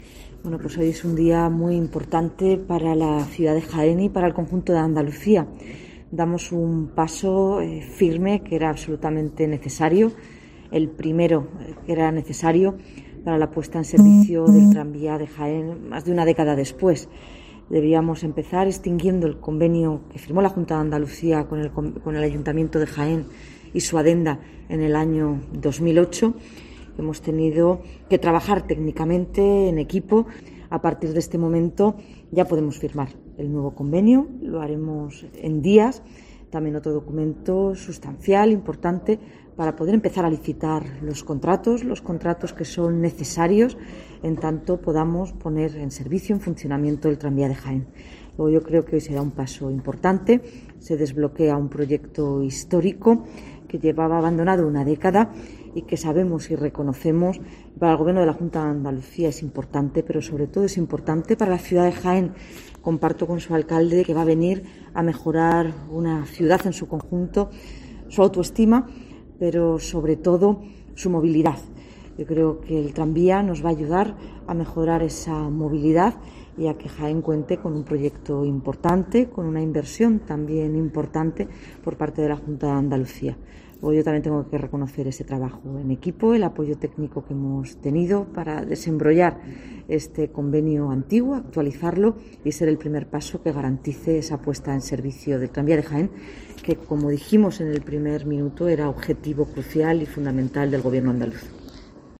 Marifrán Carazo es consejera de Fomento